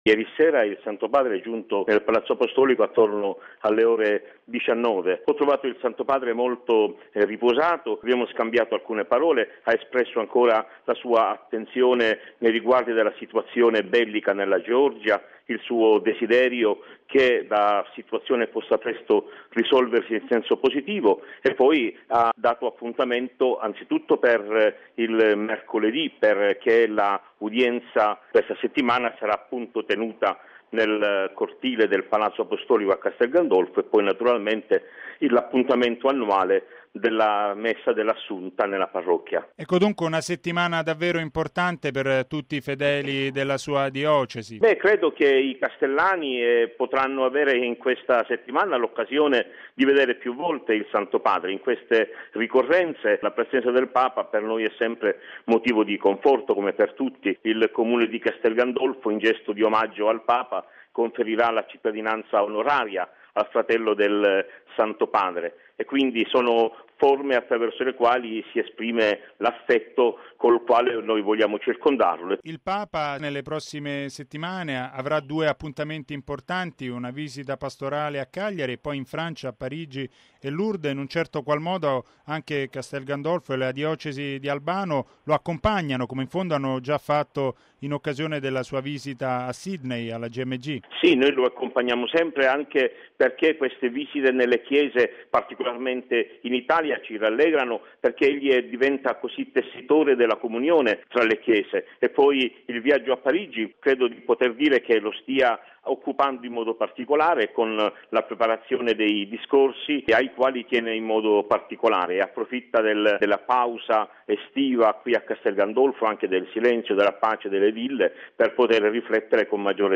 Il vescovo di Albano, mons. Semeraro, ai nostri microfoni: il Papa segue la situazione in Georgia ed auspica una soluzione positiva